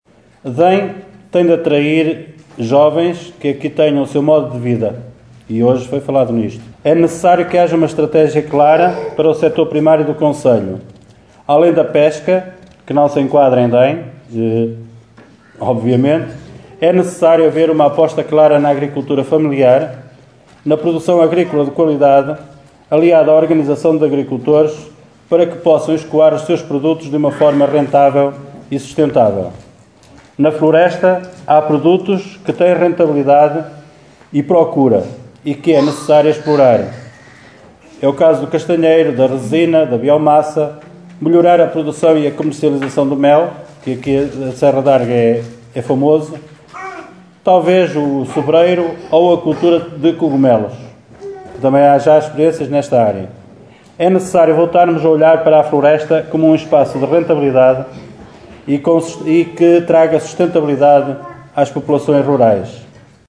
Foi o que fez o vereador do PSD, Flamiano Martins, que sublinhou as potencialidades da aldeia de Dem.